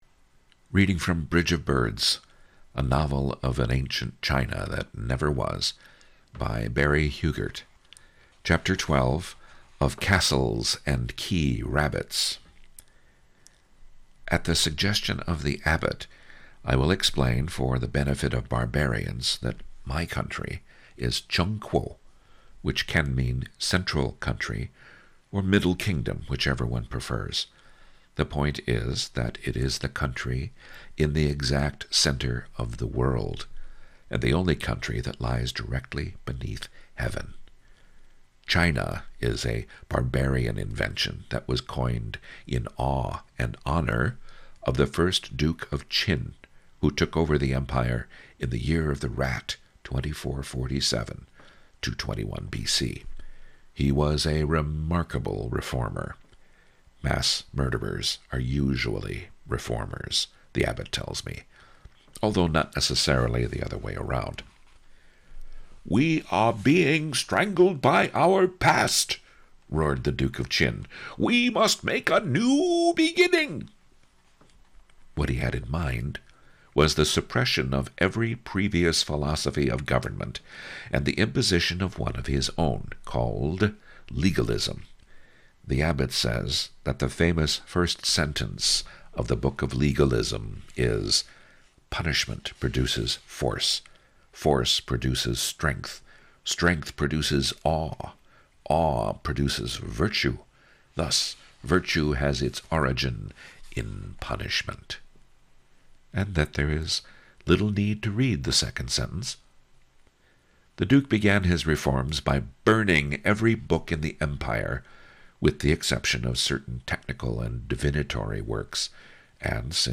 If you enjoy the reading, please leave a comment below!